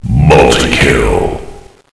flak_m/sounds/announcer/int/multikill.ogg at 46d7a67f3b5e08d8f919e45ef4a95ee923b4048b
multikill.ogg